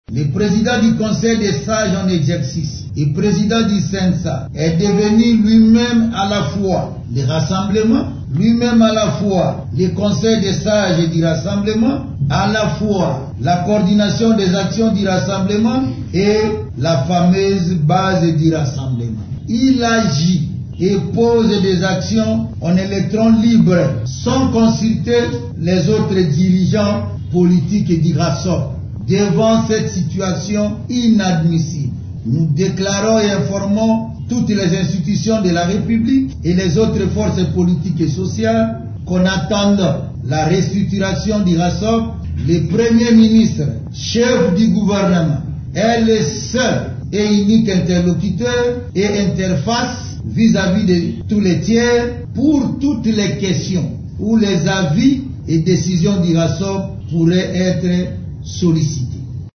Lisant la déclaration commune, Lisanga Bonganga, ministre d’Etat chargé des relations avec le Parlement et coordonnateur de la CAT, Coalition des Alliés de Tshisekedi, accuse Joseph Olenghankoy de s’arroger des pouvoirs illimités :